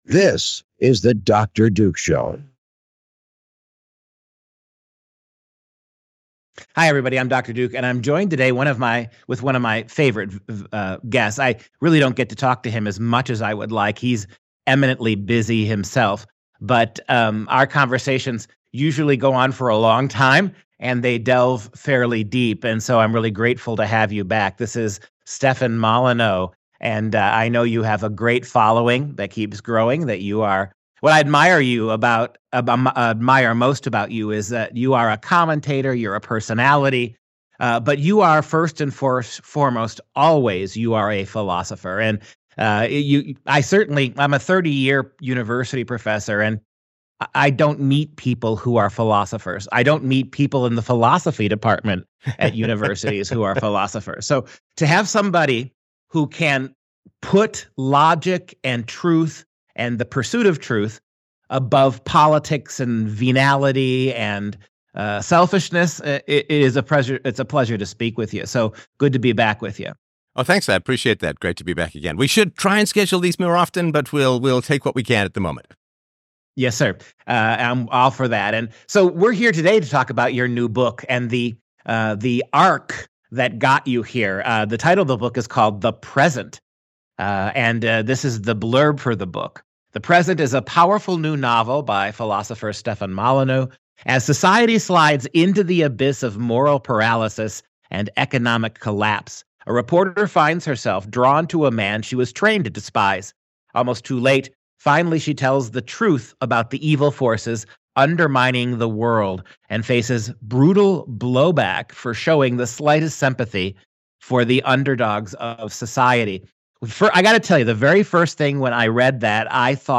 5500 'The Present' - Stefan Molyneux in Conversation